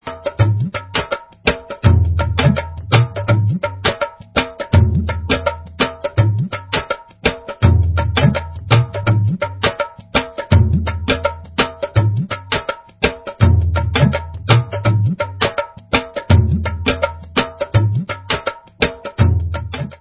Dholak & Dhol 2